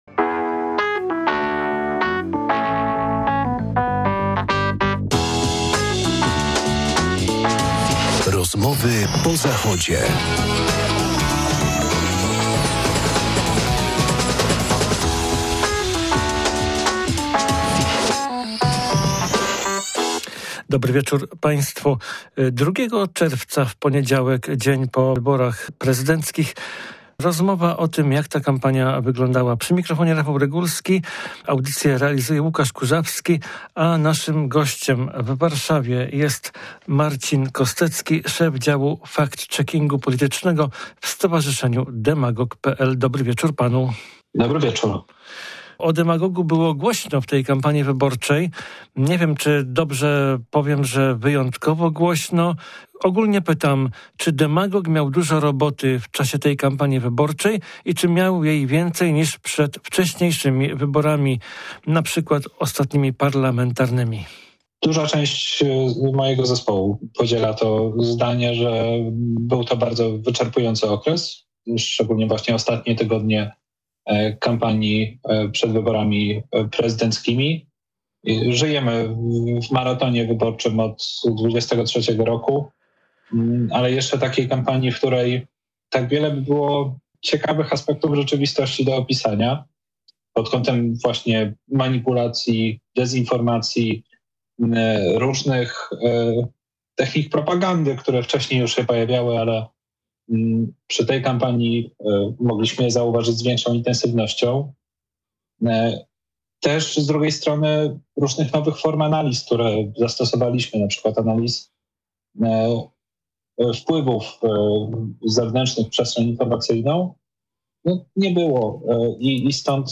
Dzień po wyborach rozmawialiśmy z jednym z jego bohaterów, czyli... Demagogiem.